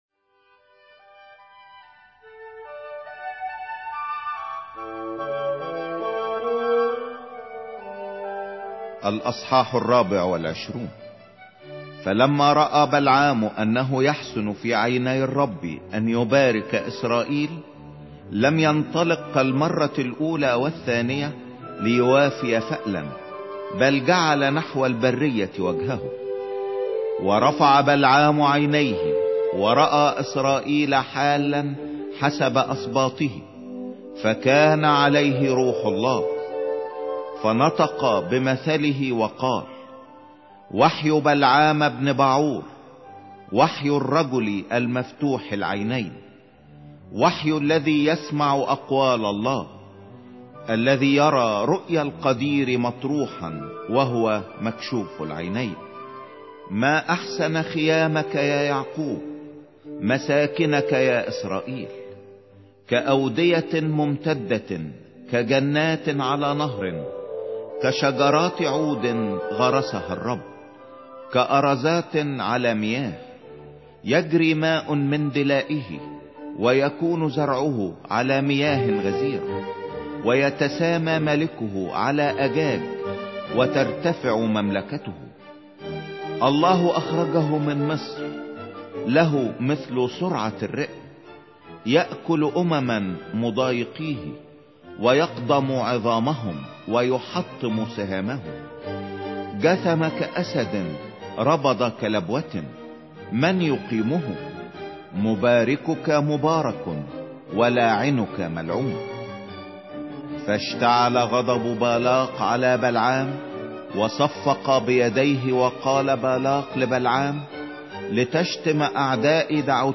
سفر العدد 24 مسموع